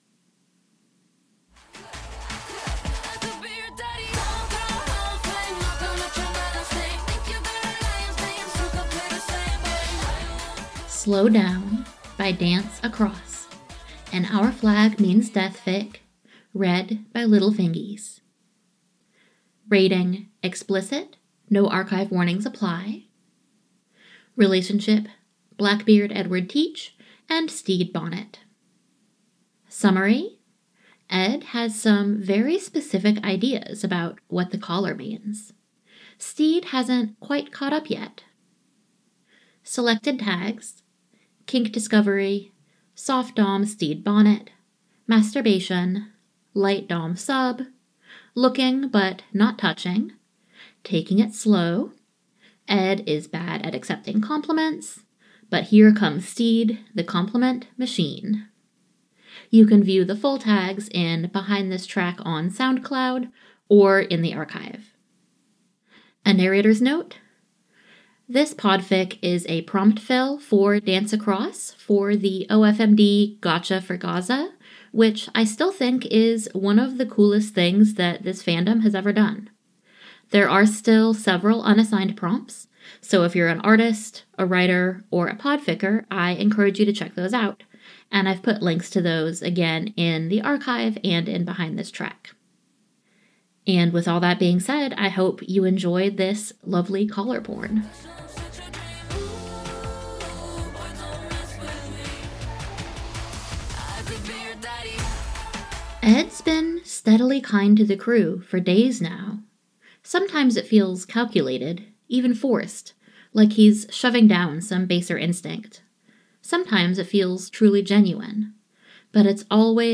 with background music: download mp3: here (r-click or press, and 'save link') [29 MB, 00:29:53] download m4b: here (r-click or press, and 'save link') [28 MB, 00:29:53]